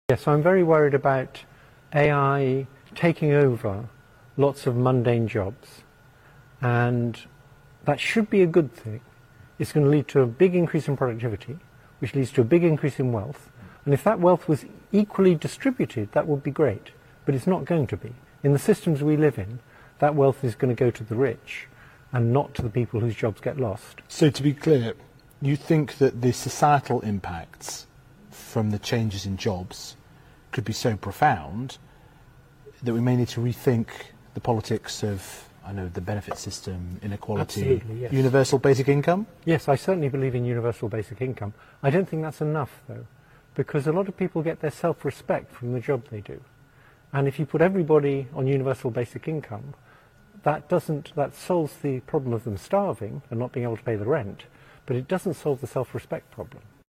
In a BBC interview, Hinton, who recently departed Google to speak more openly about AI risks, highlighted the potential for AI to exacerbate job loss and increase inequality. He suggests that UBI could provide a stable income to all, easing the transition affected by AI advancements.